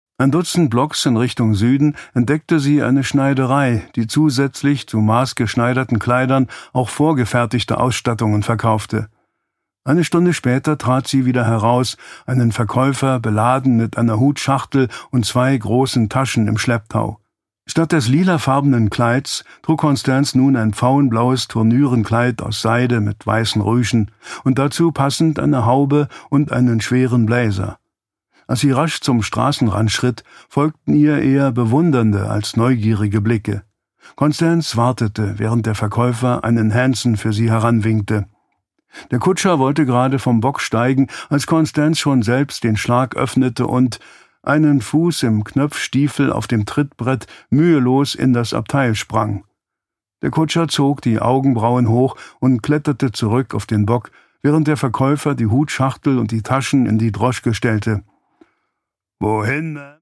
Produkttyp: Hörbuch-Download
Gelesen von: Detlef Bierstedt